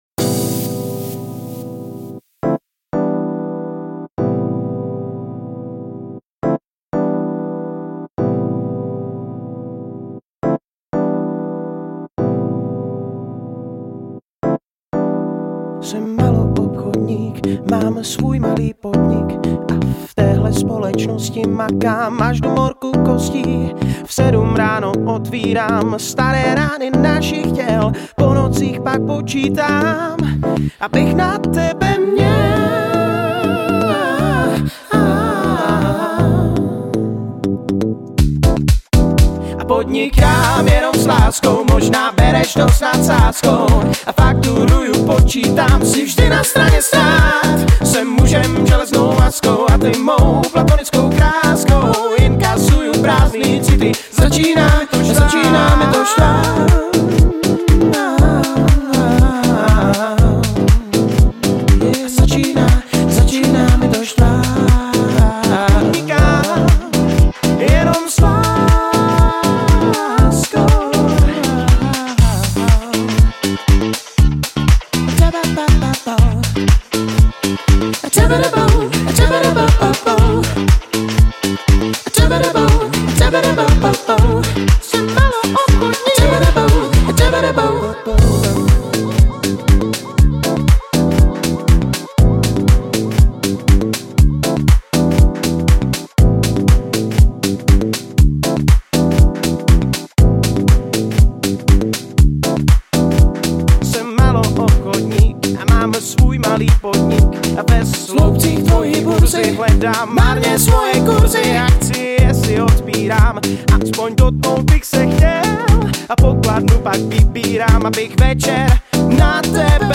Žánr: Pop
CD bylo nahráno ve známém ostravském studiu Citron.